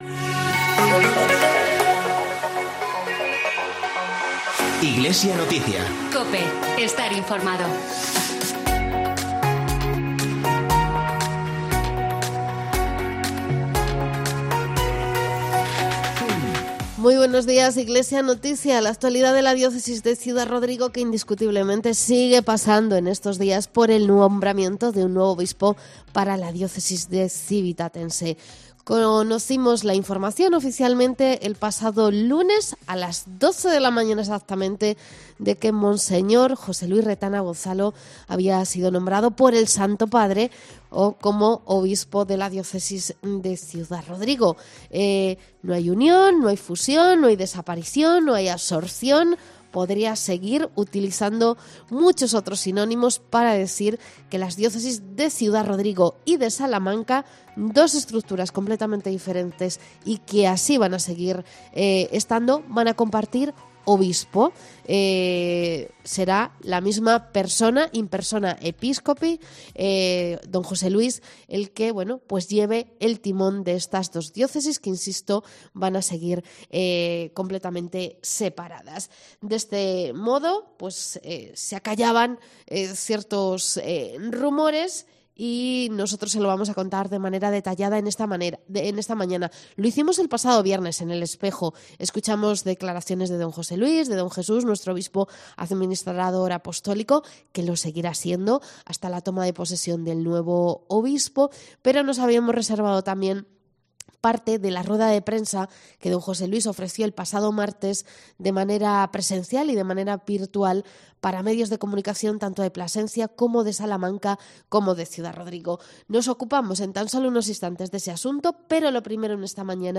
AUDIO: Resumen rueda de prensa del nuevo Obispo de Ciudad Rodrigo Monseñor José Luis Retana.